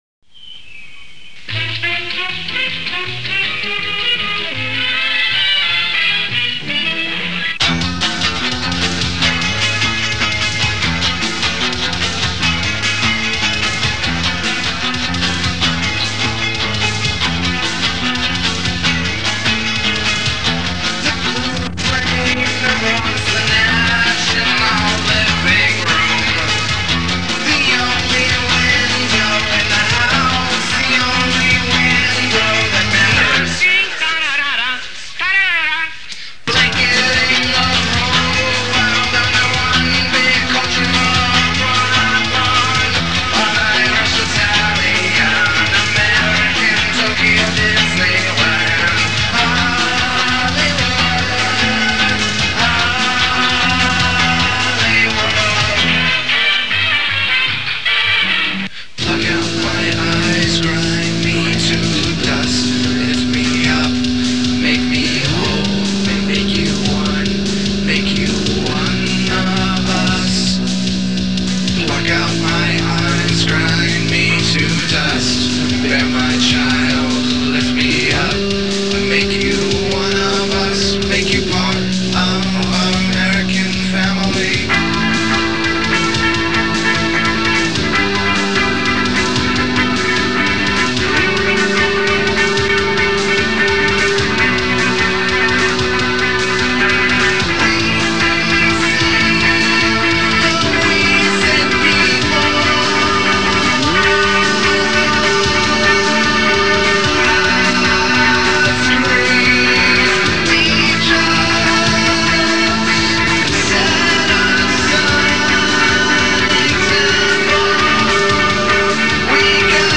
They didn't have a drummer, so they used a drum machine.
Very rough, early versions
with a home recorder and drum machine